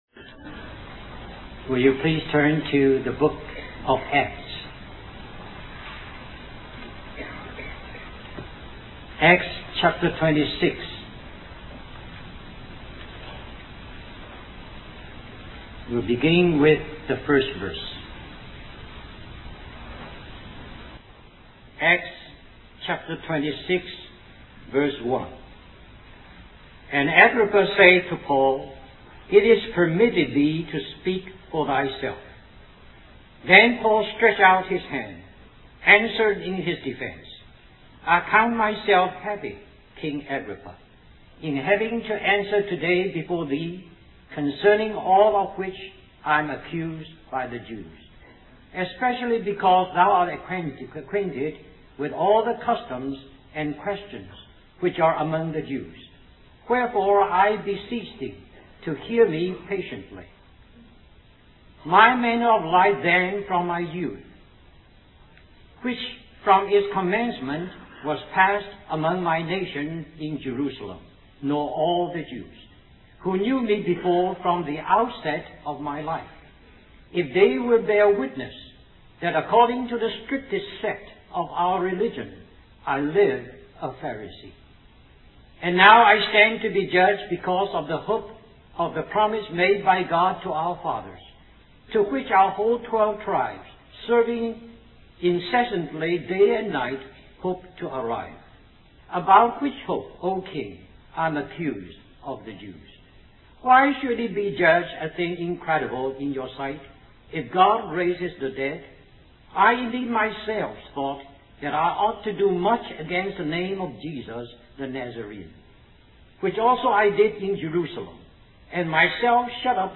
1994 Christian Family Conference Stream or download mp3 Summary This message is continued on the message found here .